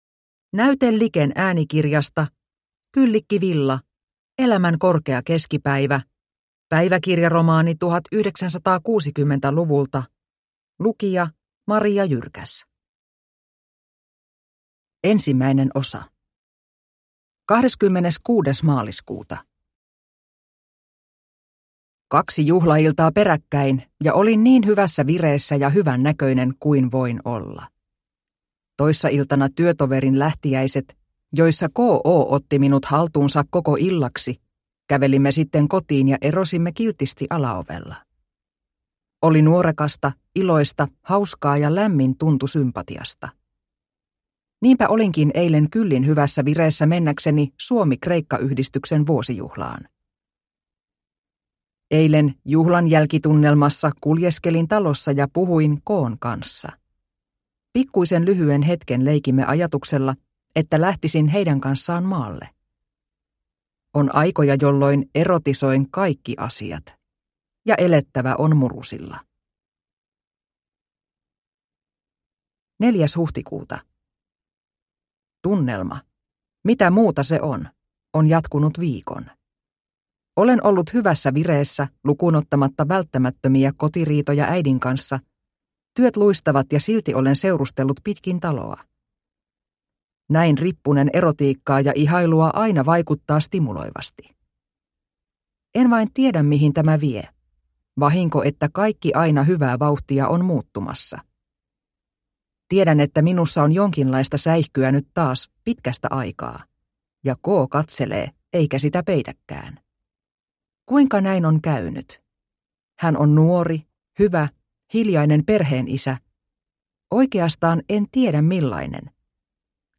Elämän korkea keskipäivä – Ljudbok – Laddas ner